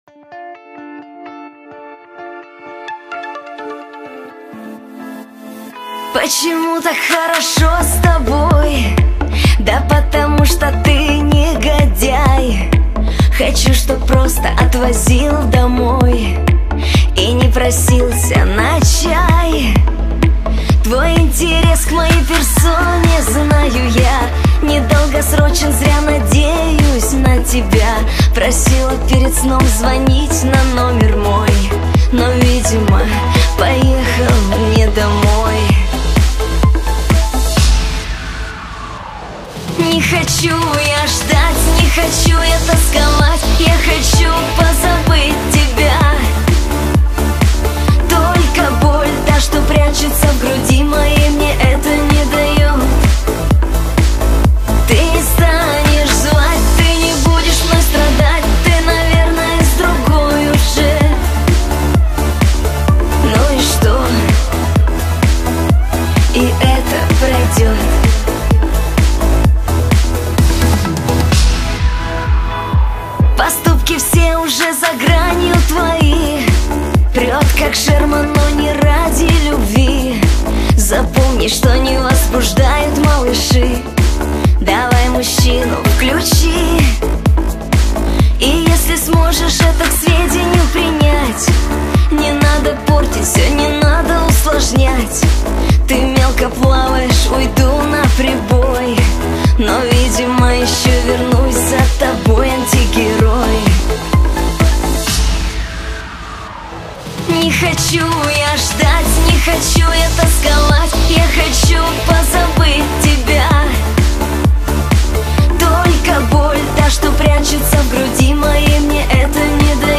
На шансоне.
К эстрадному направлению